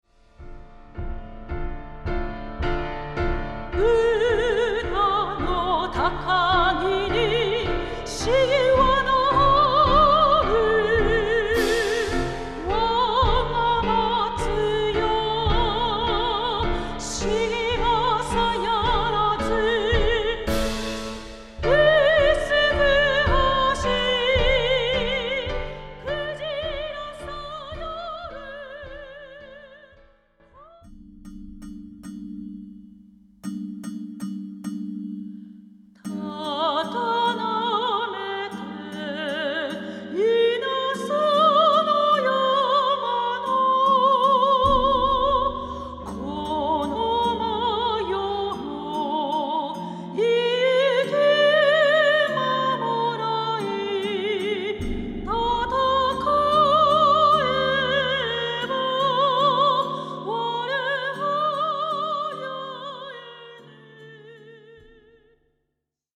和歌劇作品紹介